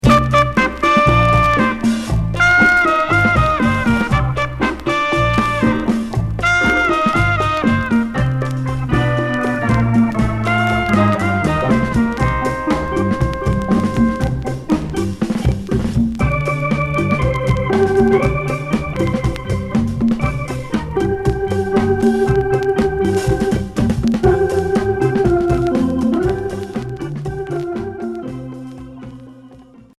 Pop Unique EP retour à l'accueil